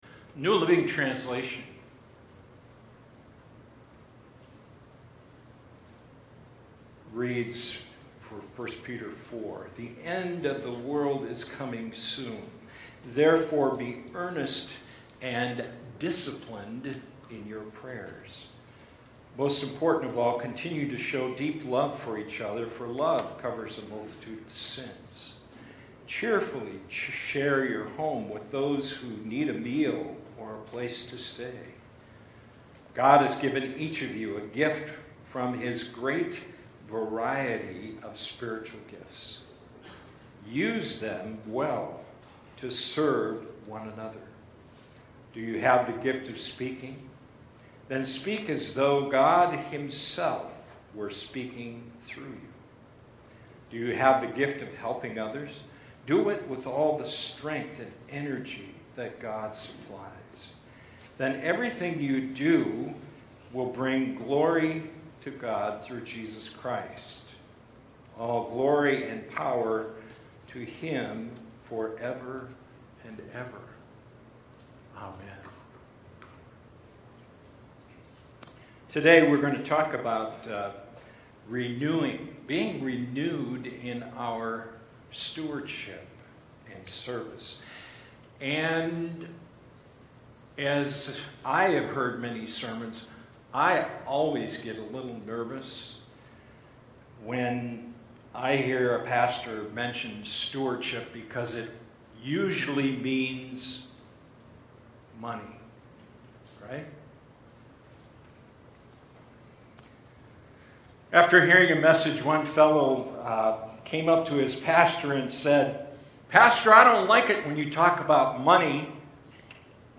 Sermons | Faith Covenant Church